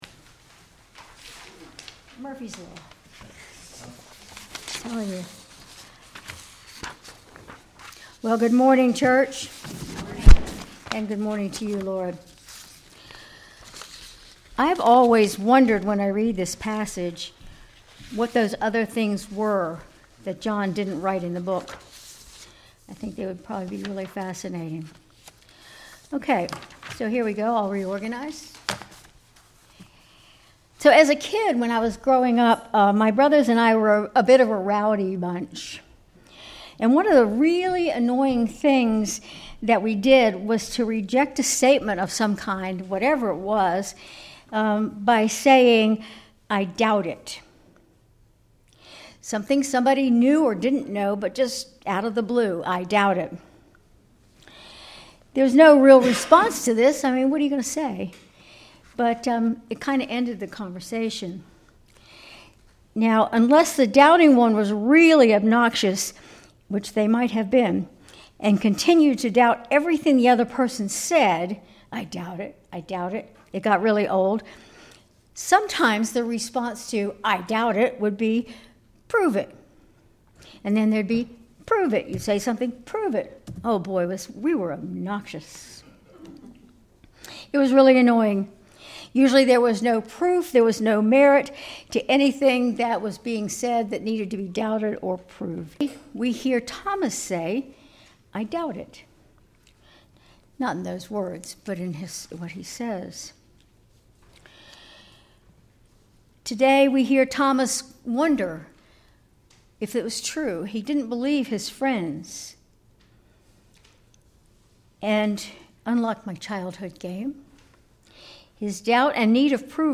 Sermon April 27, 2025